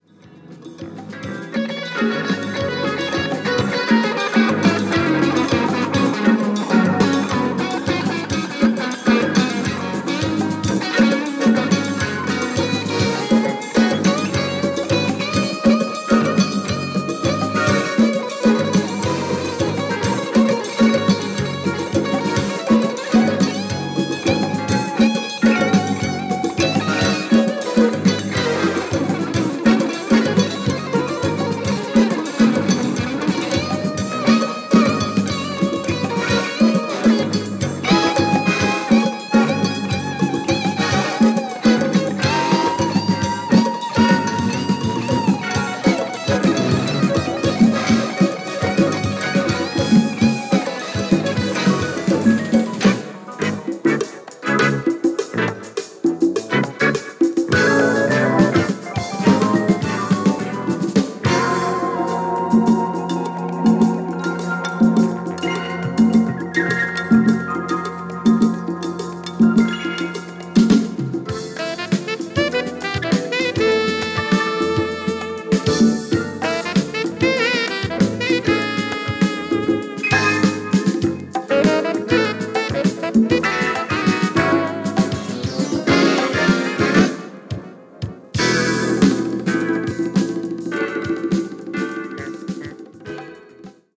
Fusion Jazz Band